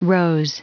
Prononciation du mot rose en anglais (fichier audio)
Prononciation du mot : rose